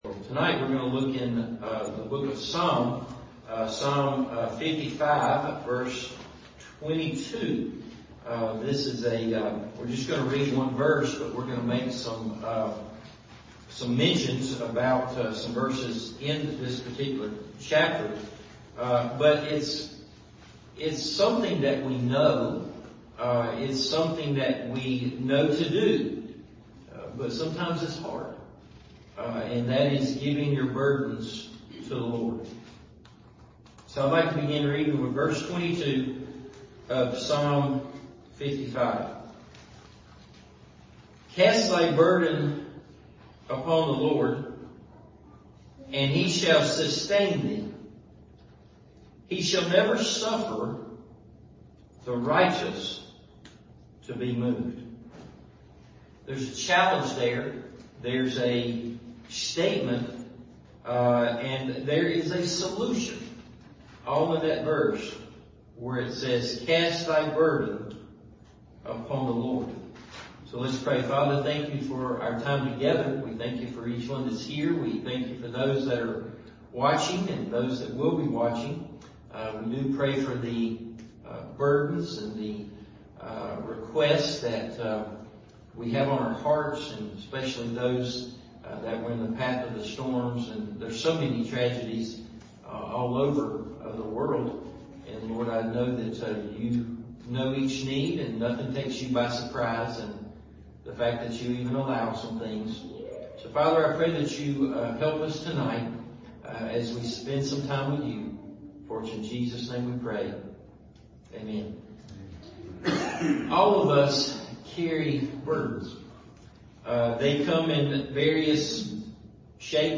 Giving Your Burdens To The Lord – Evening Service